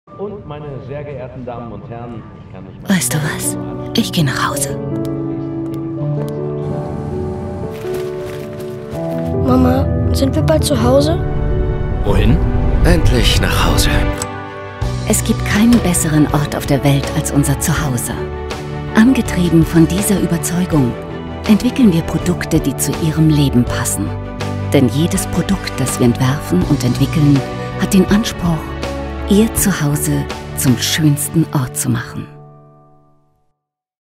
Werbung TV – Auswahl